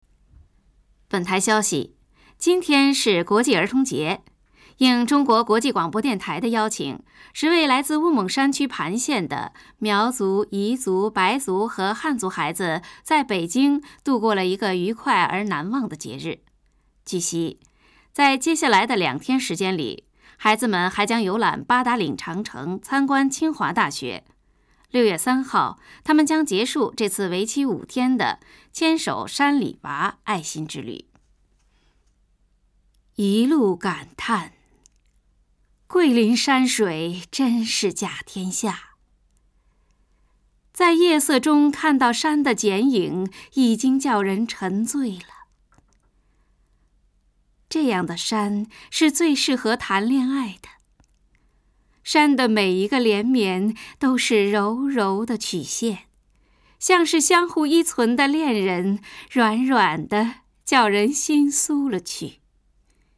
中国語ナレーター・ナレーション
北京語